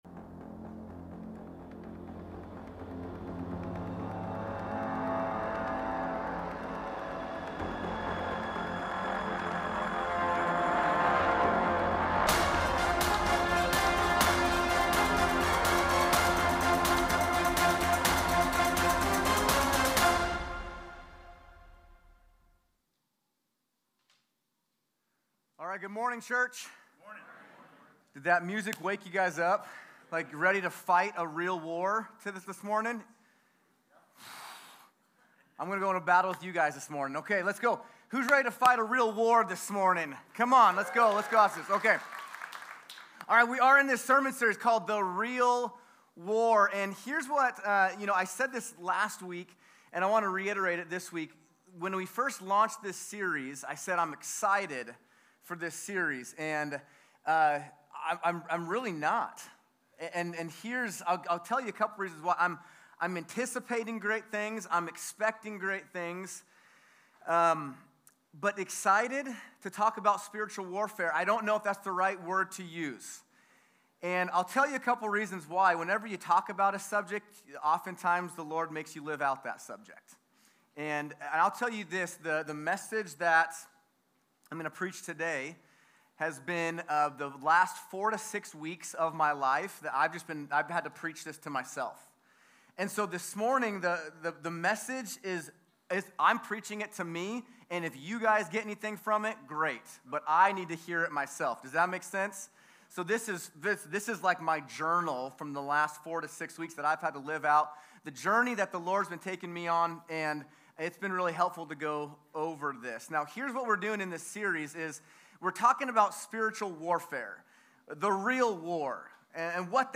Sermon+1.19.25+Audio.mp3